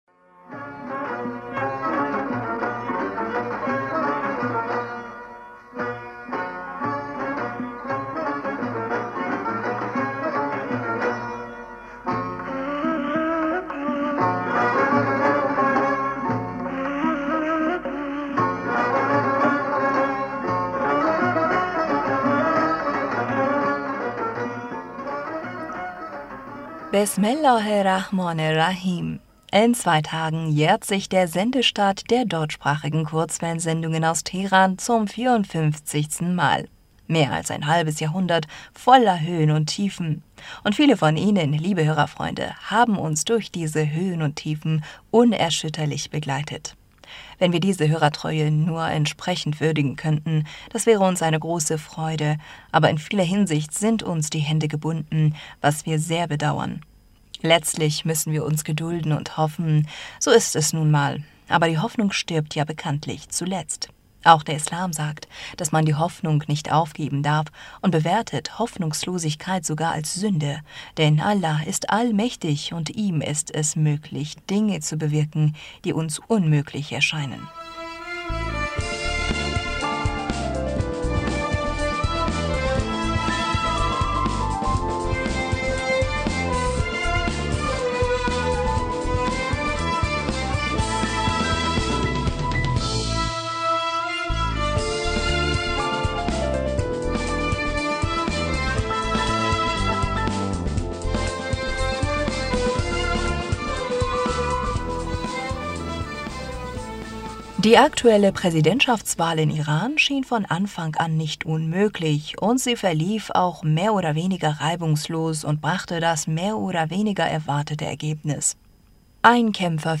Hörerpostsendung am 20. Juni 2021 Bismillaher rahmaner rahim - In zwei Tagen jährt sich der Sendestart der deutschsprachigen Kurzwellensendungen aus Teher...